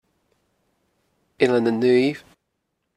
Click to hear placename audio